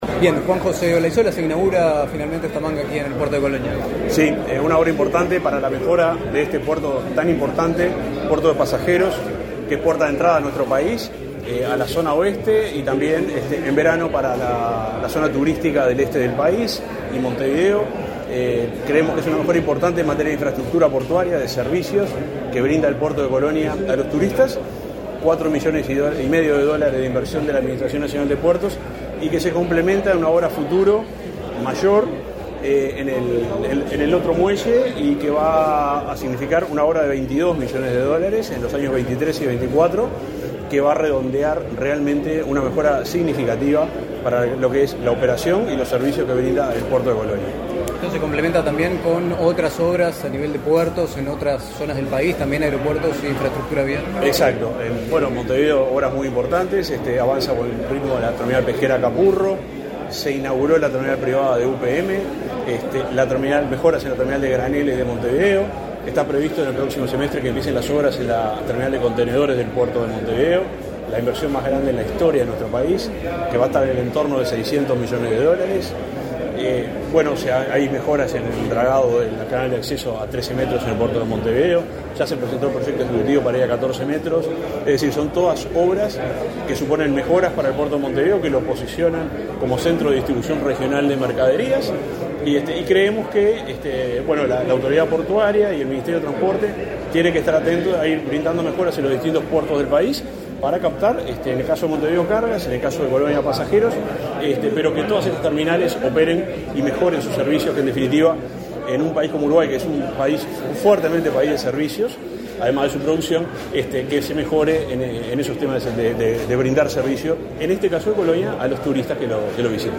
Entrevista al subsecretario del MTOP, Juan José Olaizola
Entrevista al subsecretario del MTOP, Juan José Olaizola 21/12/2022 Compartir Facebook Twitter Copiar enlace WhatsApp LinkedIn El subsecretario del Ministerio de Transporte y Obras Públicas (MTOP), Juan José Olaizola, participó, este 21 de diciembre, en la inauguración de infraestructura en el puerto de Colonia del Sacramento. Antes del evento, realizó declaraciones a Comunicación Presidencial.